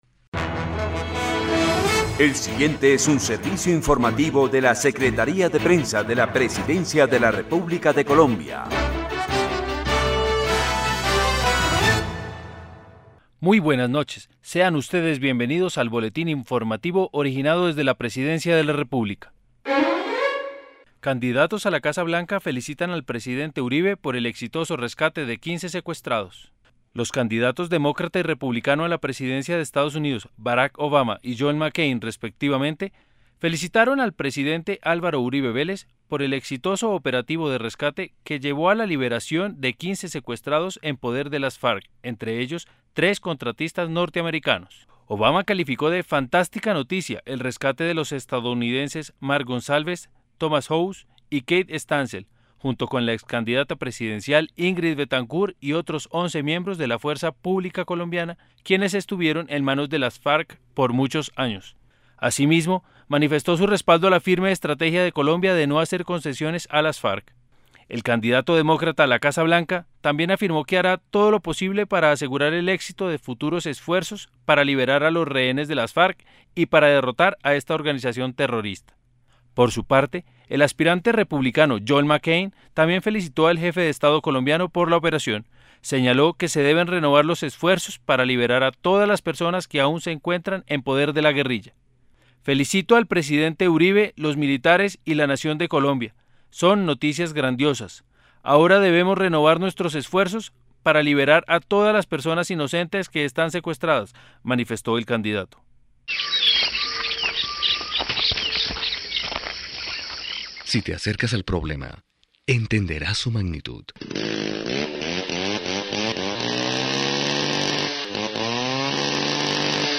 La Secretaría de Prensa de la Presidencia presenta a sus usuarios un nuevo servicio: El Boletín de Noticias, que se emite de lunes a viernes, cada tres horas, por la Radio Nacional de Colombia, en las frecuencias 570 AM y 95.9 FM.